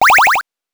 powerup_5.wav